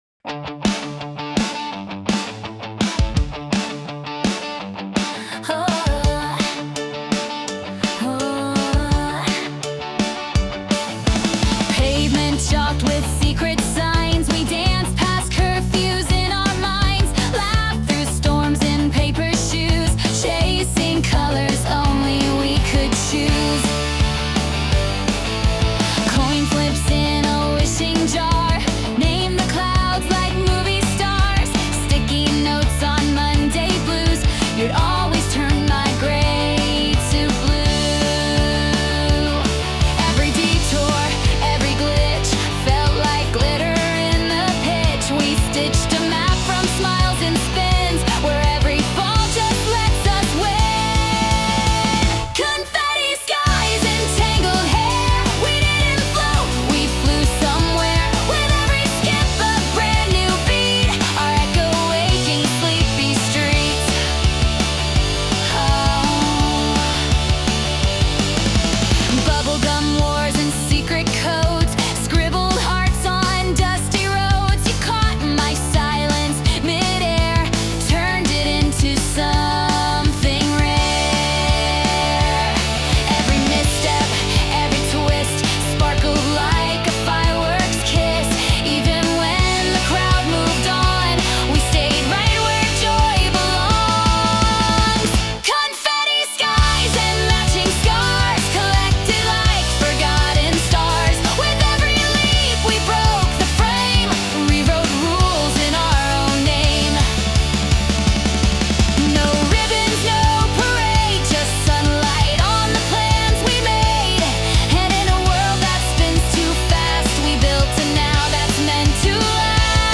洋楽女性ボーカル著作権フリーBGM ボーカル
著作権フリーオリジナルBGMです。
女性ボーカル（洋楽・英語）曲です。